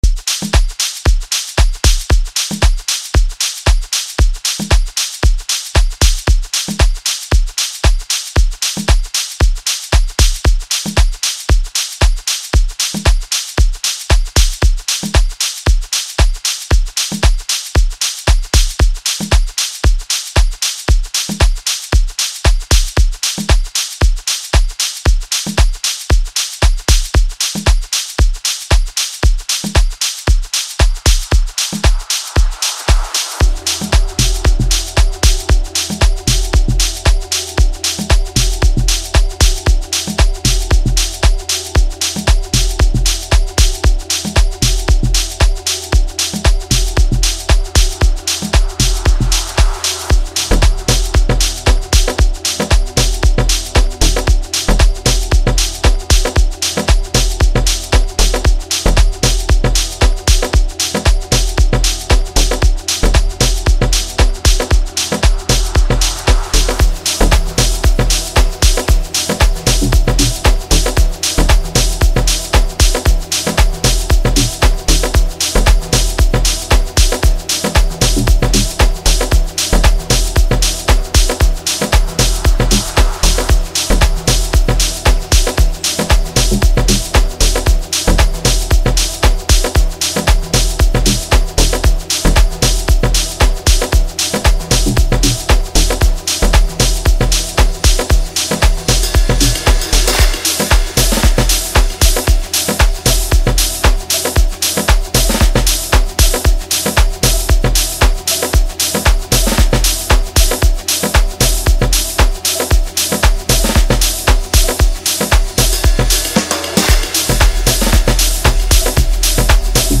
Home » Amapiano
Gifted vocalist